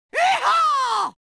Звуки ковбоев, дикого запада
• Качество: высокое
Крик ковбоя иха в вестерне